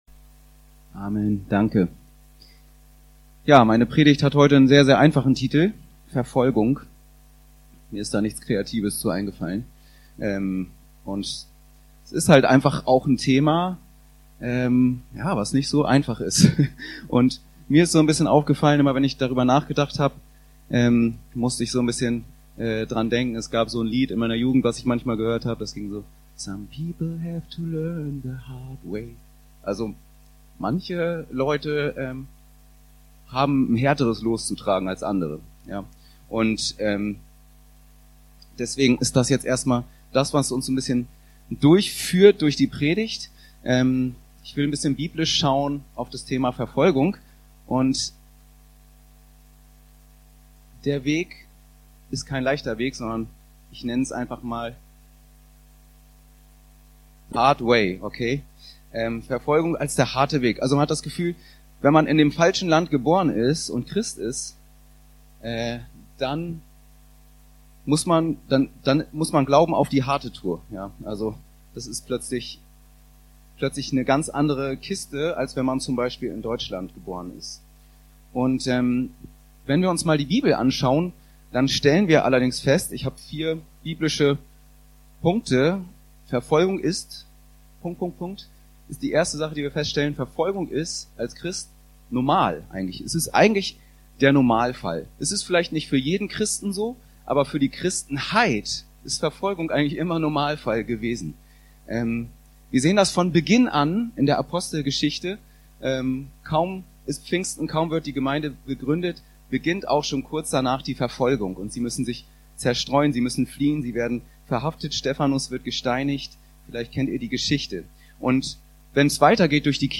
Verfolgung ~ Anskar-Kirche Hamburg- Predigten Podcast